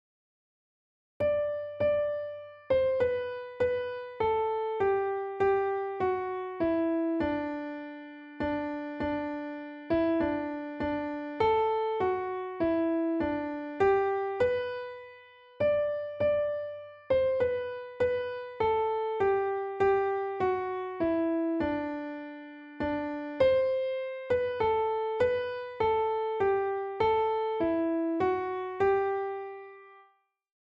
This one is a German folk song and is the most common version sung in America. It starts s-s-f-m-m-r-d-d-t,-l,-s, and is in 3/4 time. * With many clear examples of the rhythm "tum ti" (dotted crotchet - quaver) this is a great song to prepare and practise this rhythmic element in 3/4 time.